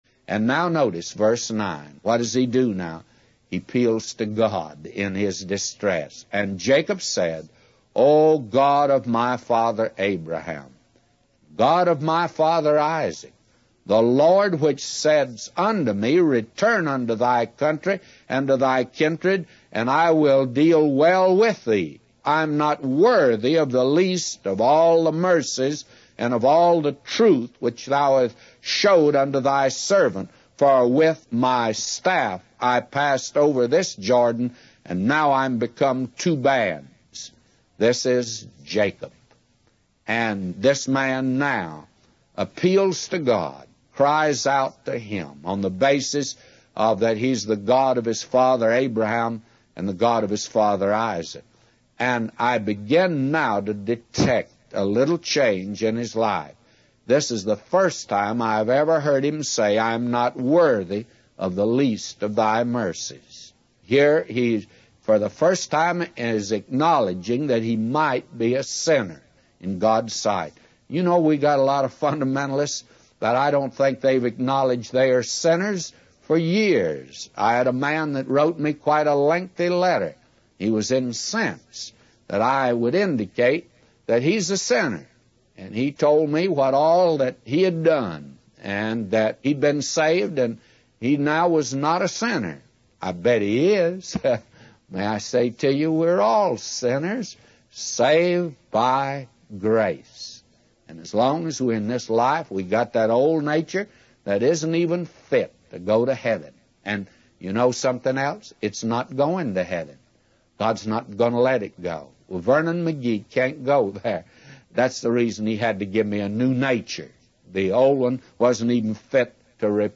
In this sermon, the preacher focuses on the story of Jacob from the Bible.